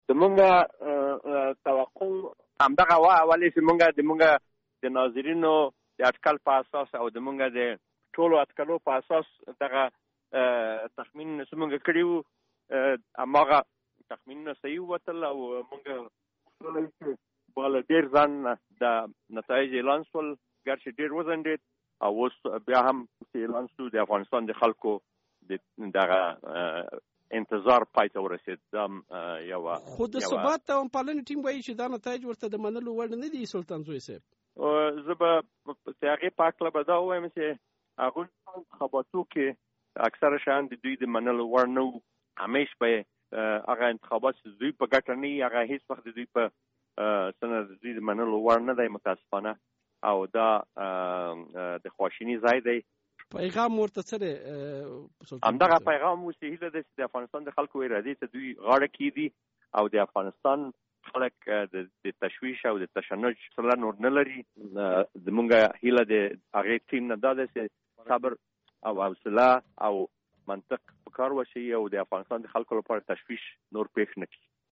له داوود سلطان‌زوی سره مرکه